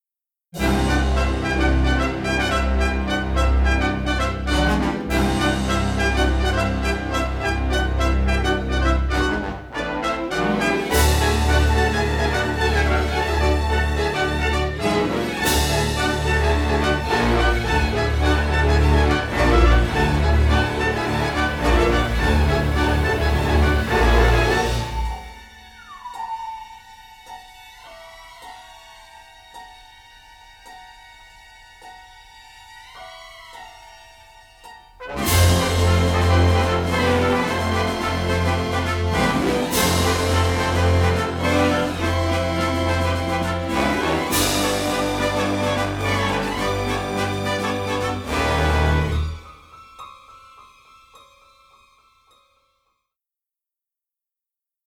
all in splendid stereo sound.
orchestra